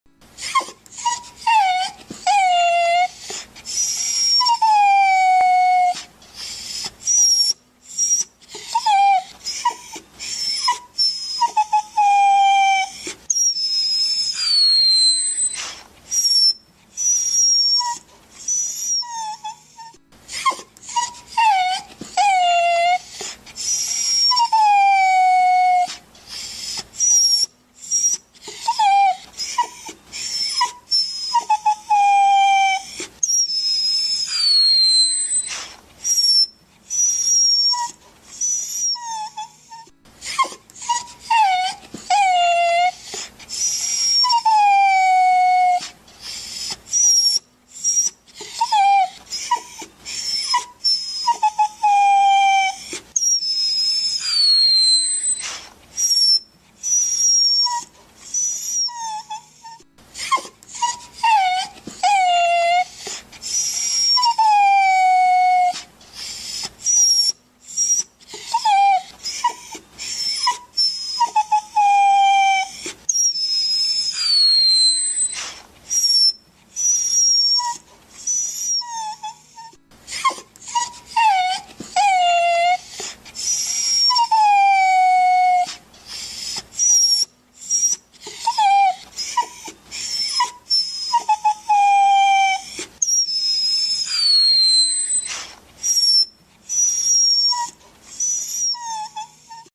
เสียงหมาร้อง (เสียใจ) ใช้แกล้งน้องหมา ชัด 100%
หมวดหมู่: เสียงสัตว์เลี้ยง
tieng-cho-keu-buon-dung-de-treu-cho-ro-100-th-www_tiengdong_com.mp3